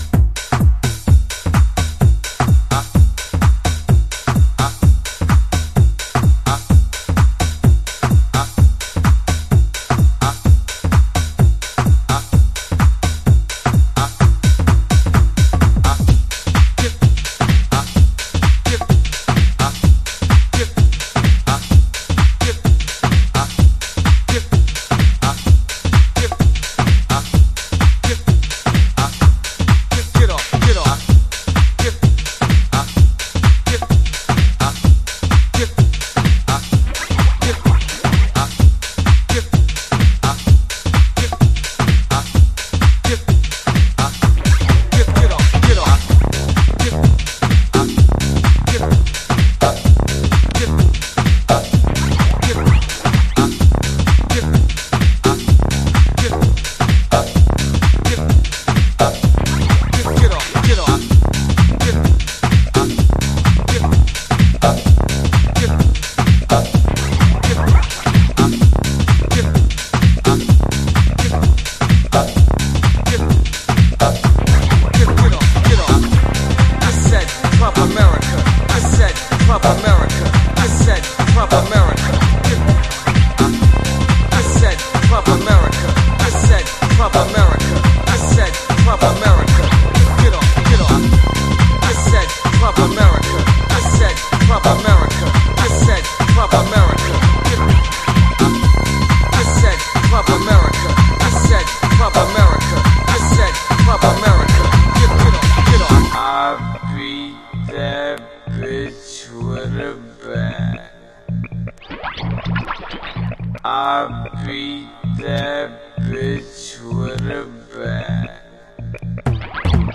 NJのゲトースタイル。